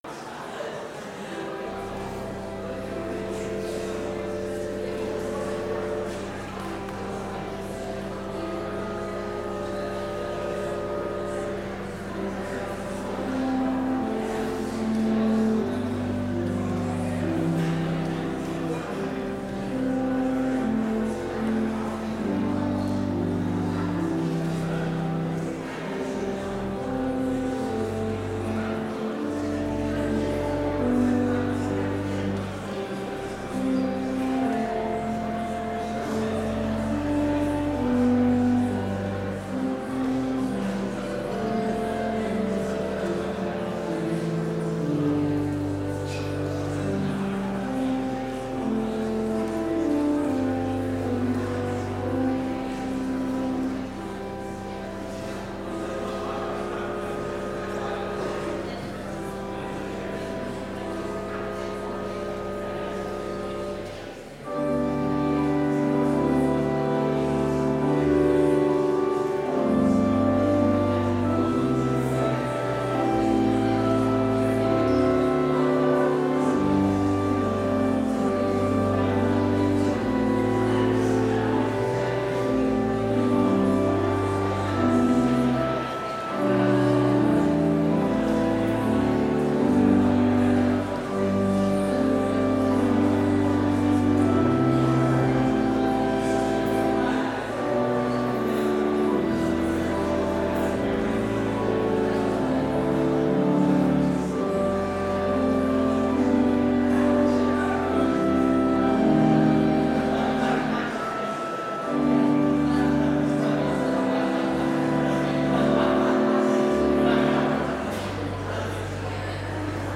Complete service audio for Chapel - January 13, 2020